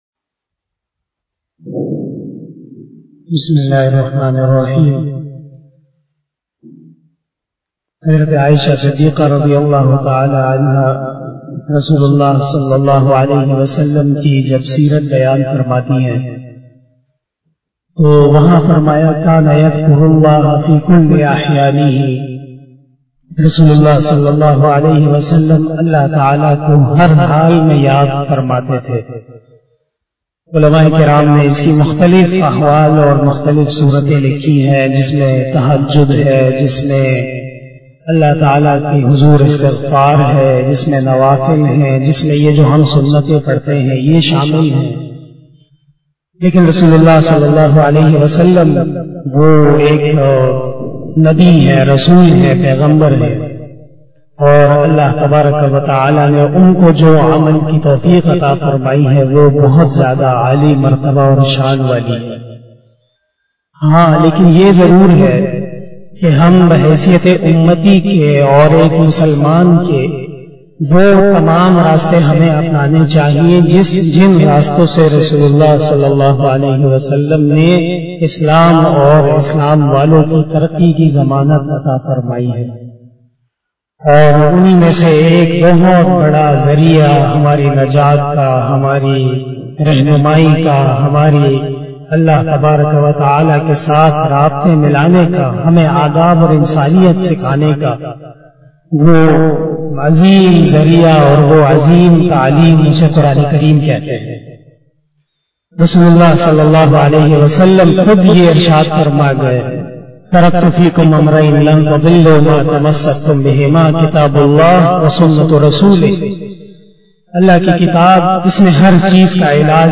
After Namaz Bayan